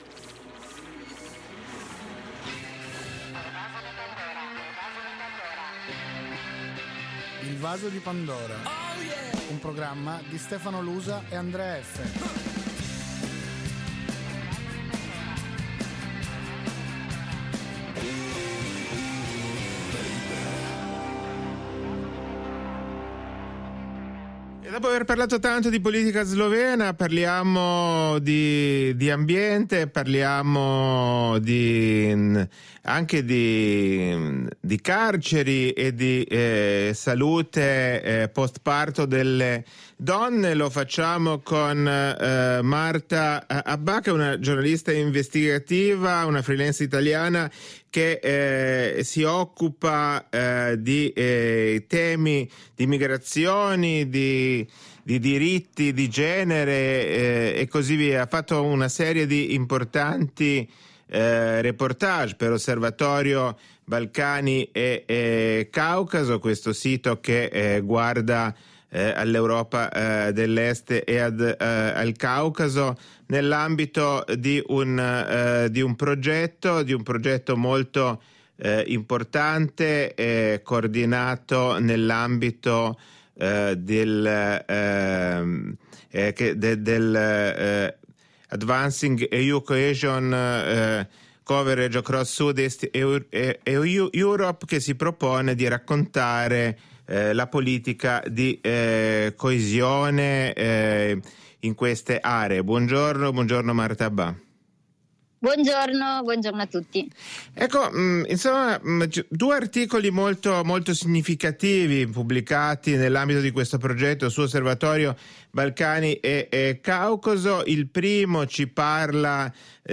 Ne ha parlato alla trasmissione “Il Vaso di Pandora” (3 aprile 2026)